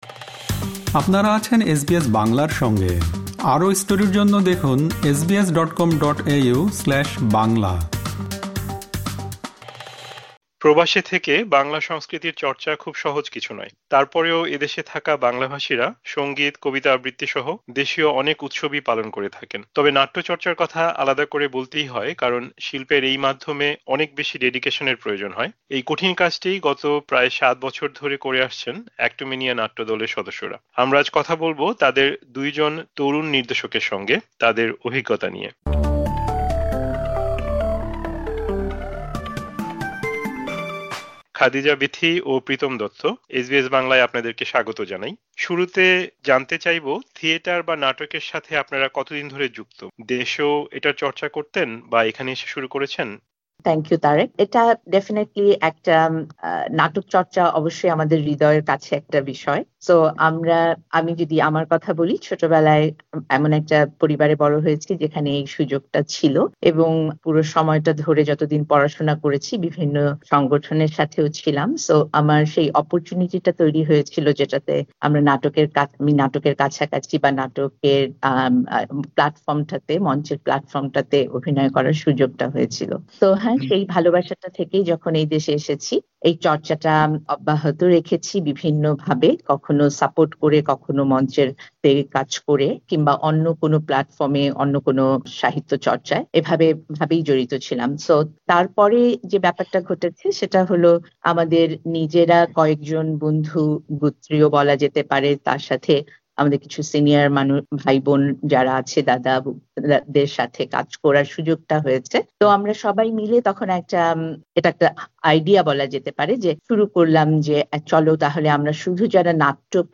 এসবিএস বাংলার সঙ্গে কথা বলেছেন